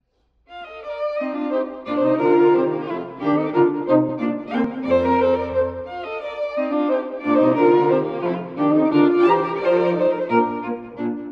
↑古い録音のため聴きづらいかもしれません！（以下同様）
優雅で、すこし遊ぶような雰囲気の最終楽章です。
“mezza voce” … 「柔らかい声で」という指示も特徴的です。